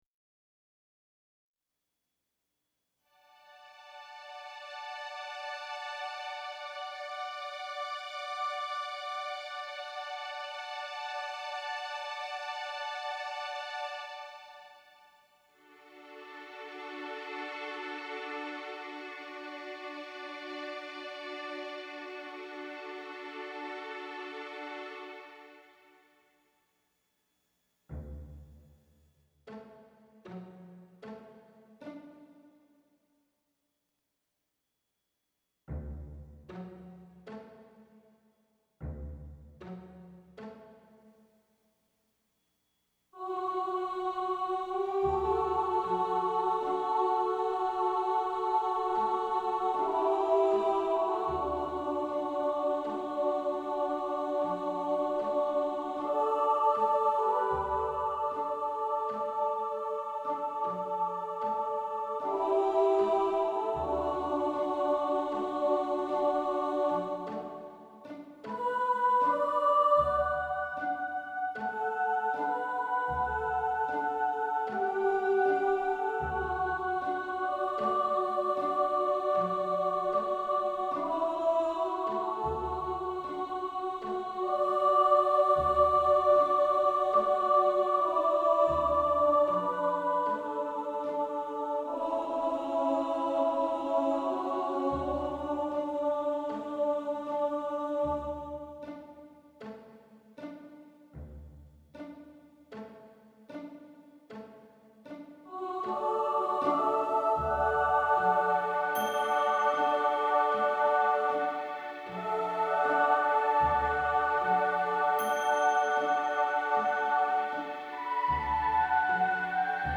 Listen to the midi mockup demo. . .
Midi Mockup MP3 Demo
for Choir & Chamber Orchestra